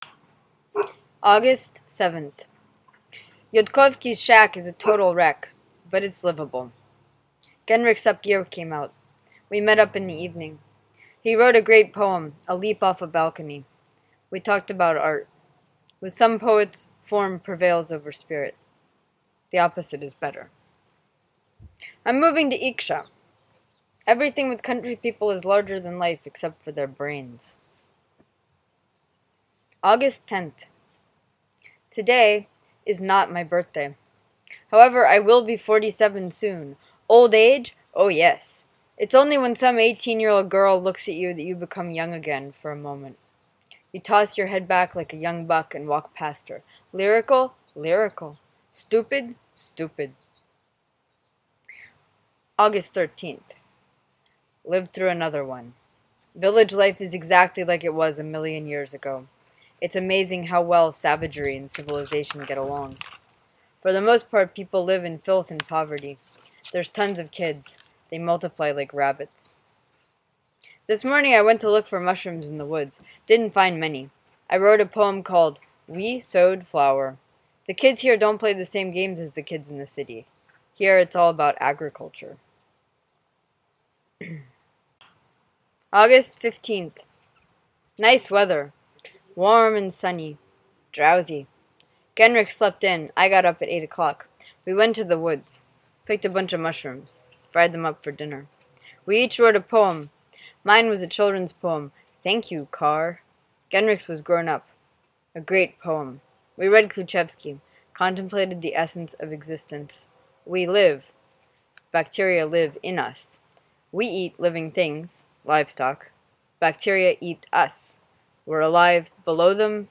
Recorded at the Knockdown Center, 52-19 Flushing Ave, Queens, NY April 14, 2016.